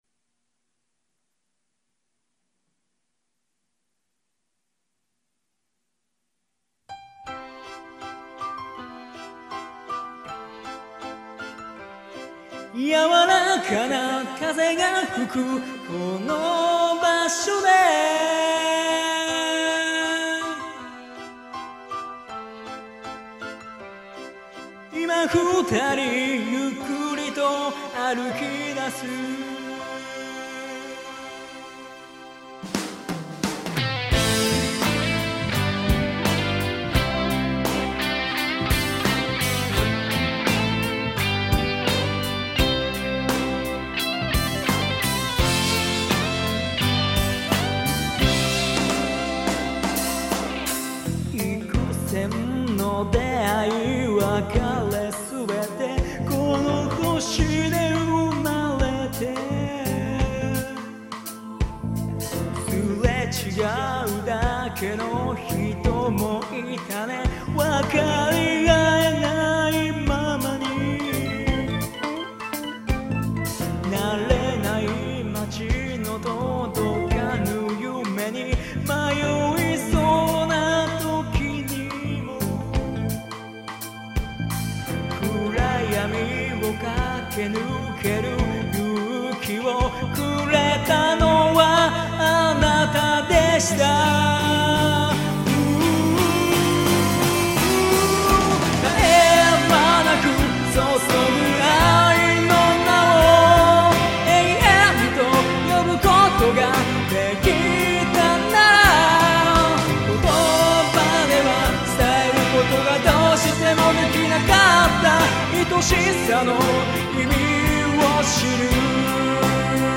オンマイク／カラオケ用マイクロフォン使用